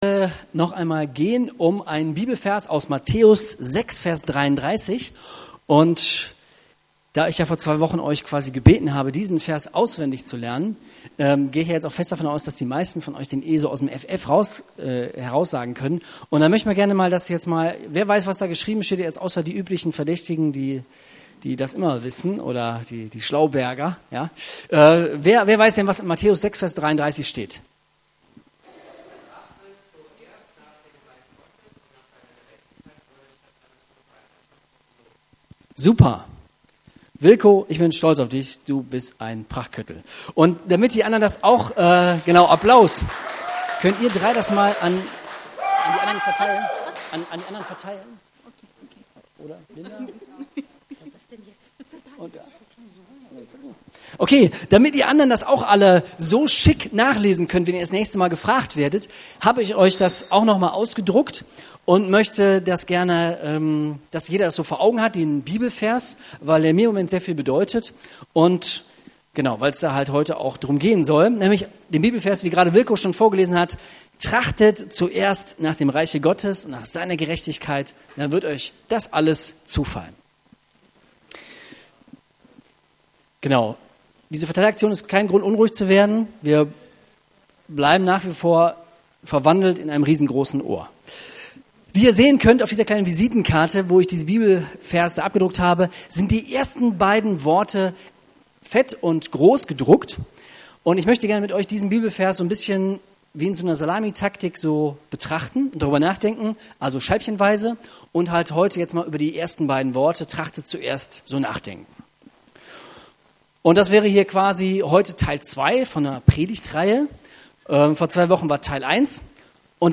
Auf dieser Seite findest du unsere Predigten der letzten 18 Jahre.